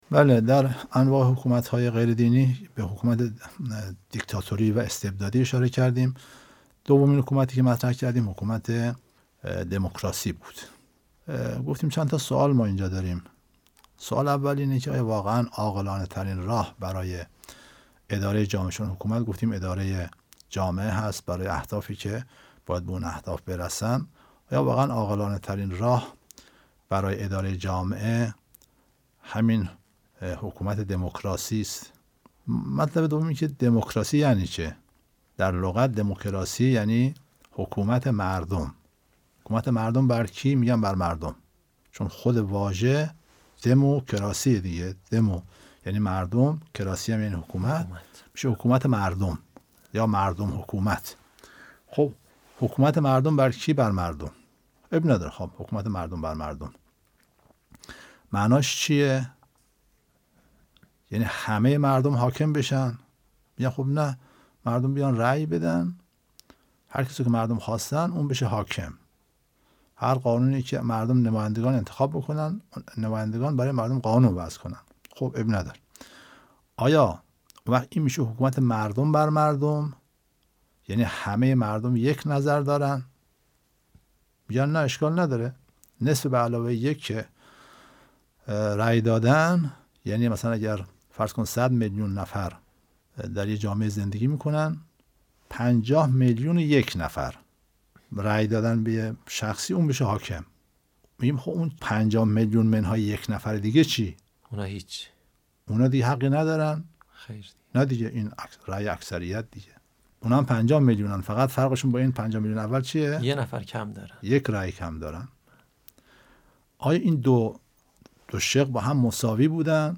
سخنرانی سیاسی اجتماعی